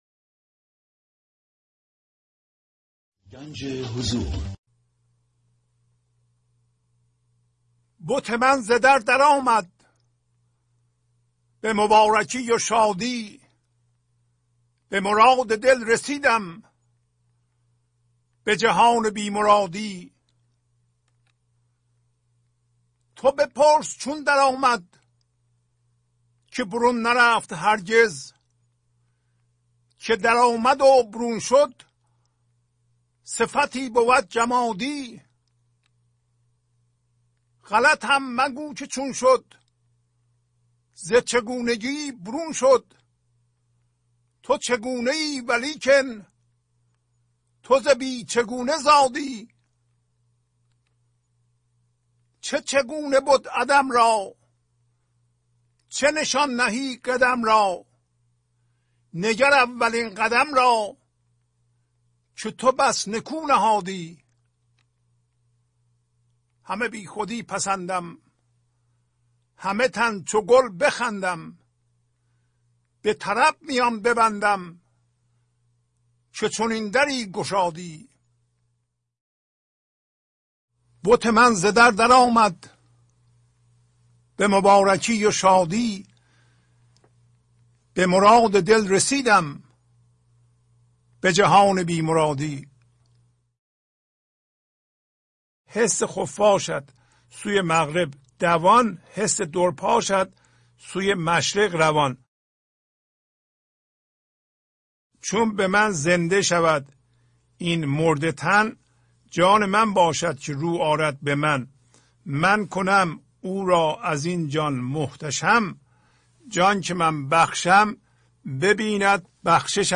خوانش تمام ابیات این برنامه - فایل صوتی
921-Poems-Voice.mp3